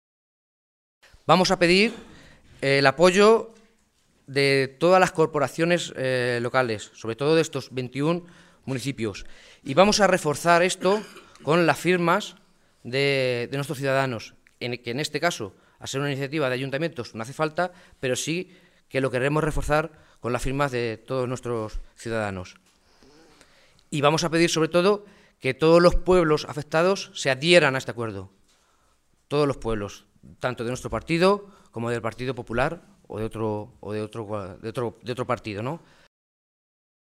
Rueda de prensa de los distintos alcaldes socialistas afectados por el cierre de los PAC en la Región
Cortes de audio de la rueda de prensa
Audio Alcalde de Tembleque-4